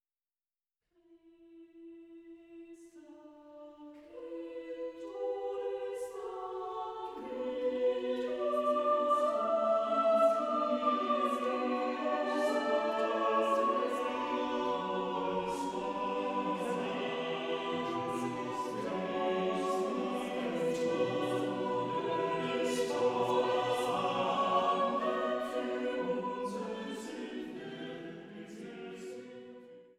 Chorwerke auf Gesänge Martin Luthers